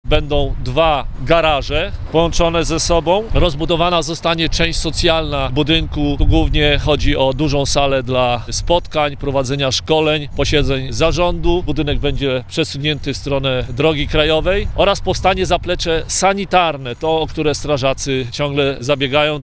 Mówi burmistrz Wiesław Ordon.